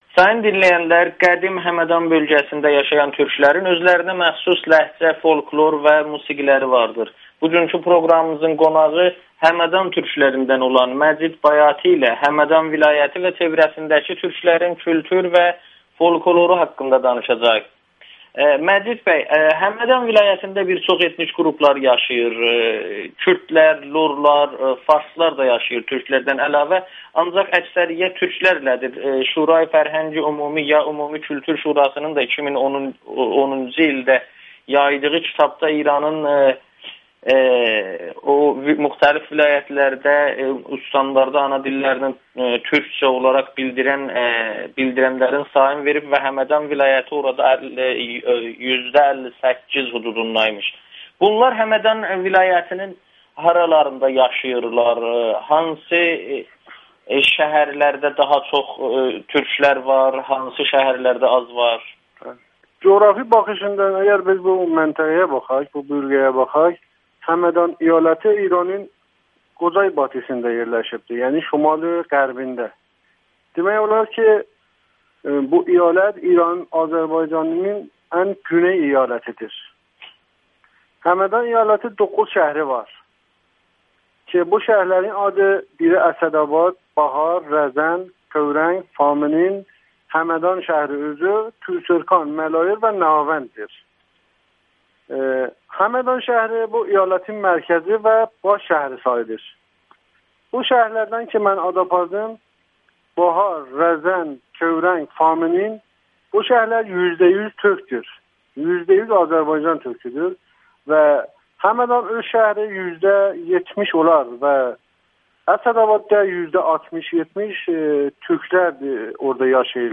Həmədanlı araşdırmaçı Amerikanın Səsinə danışır